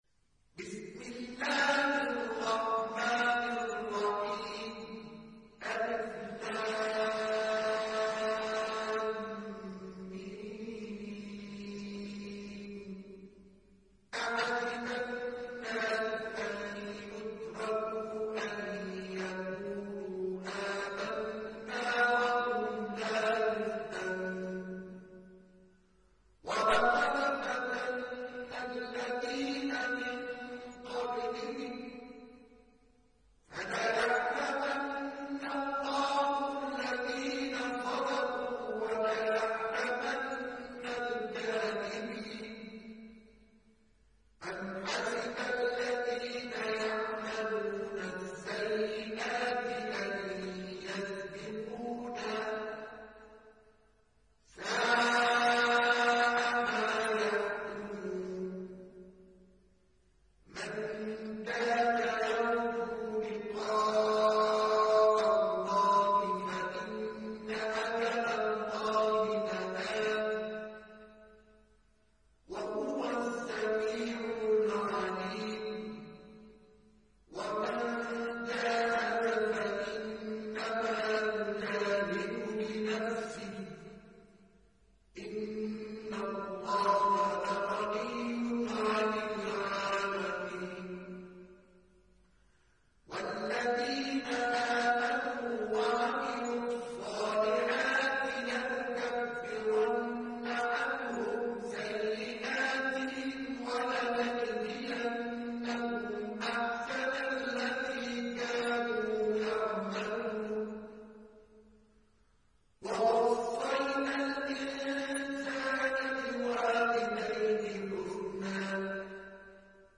دانلود سوره العنكبوت mp3 مصطفى إسماعيل روایت حفص از عاصم, قرآن را دانلود کنید و گوش کن mp3 ، لینک مستقیم کامل